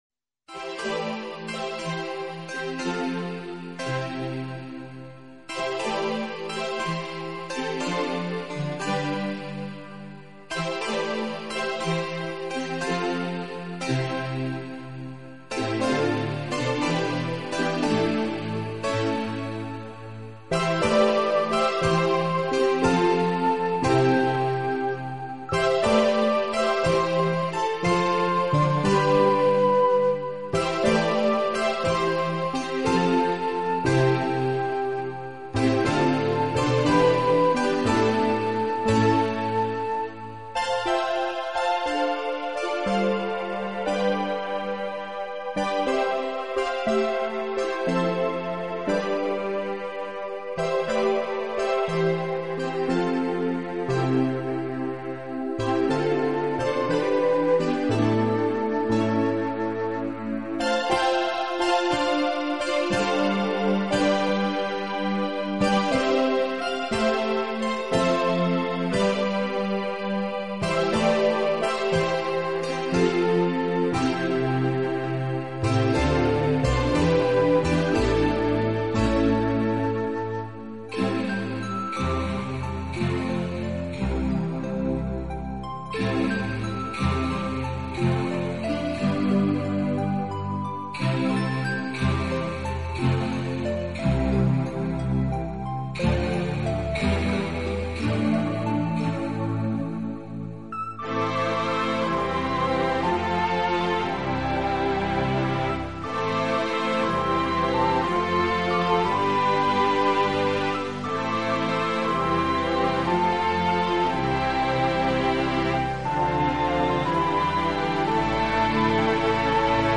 德国优秀的新世纪乐团，为二人组合。风格为南美印第安旋律
音场比较狭窄而充实，灵性被整体结构的错落层次所笼罩。